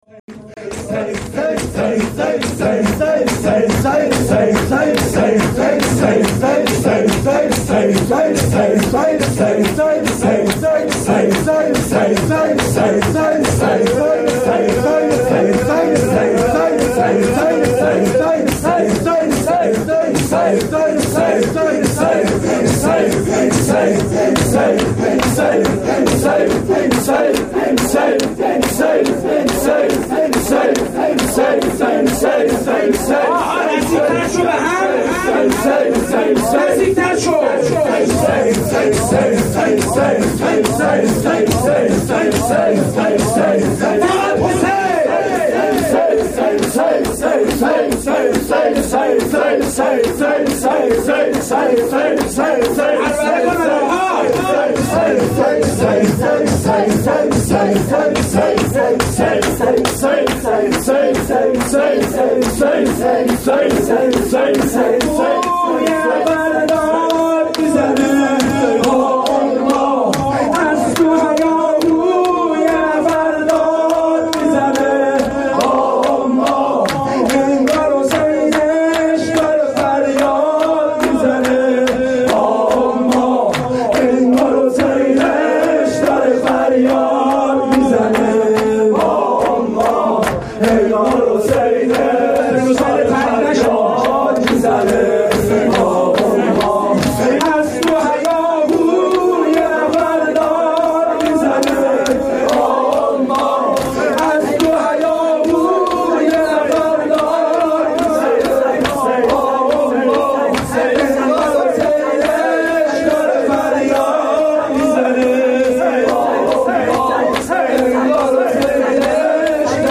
شور
هیئت رایت الهدی, فاطمیه 93